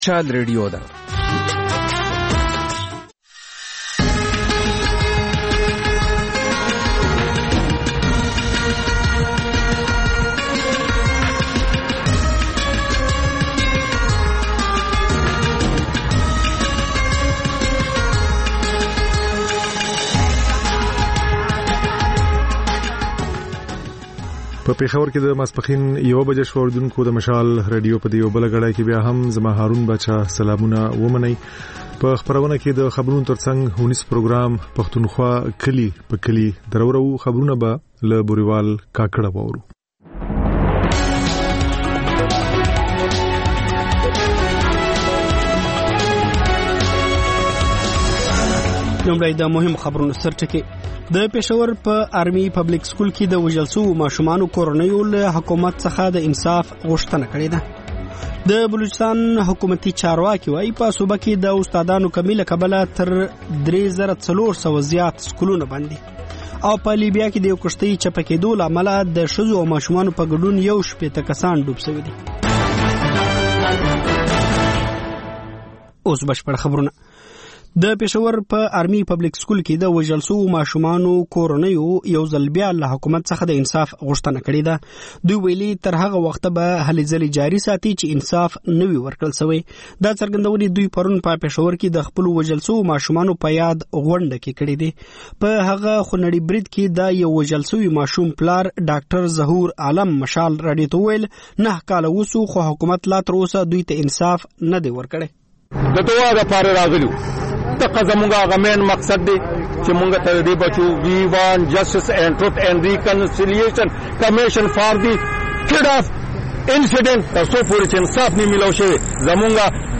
د مشال راډیو لومړۍ ماسپښينۍ خپرونه. په دې خپرونه کې تر خبرونو وروسته بېلا بېل رپورټونه، شننې، مرکې خپرېږي. ورسره اوونیزه خپرونه/خپرونې هم خپرېږي.